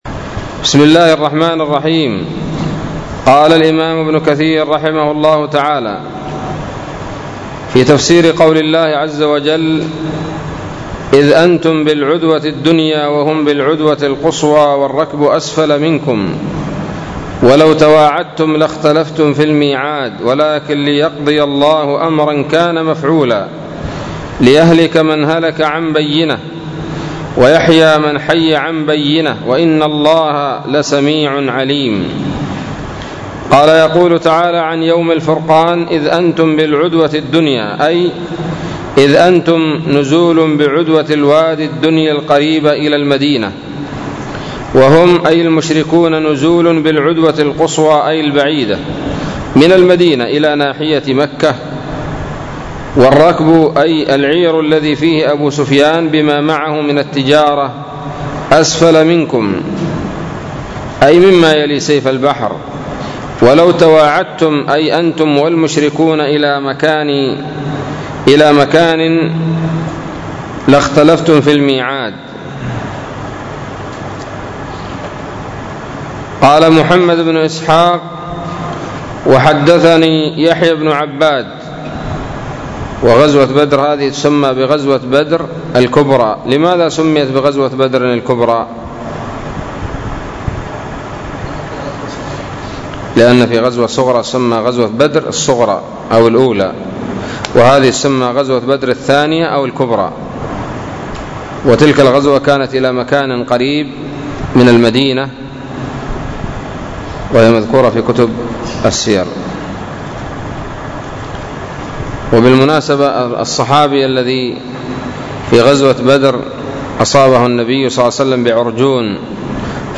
الدرس السادس والعشرون من سورة الأنفال من تفسير ابن كثير رحمه الله تعالى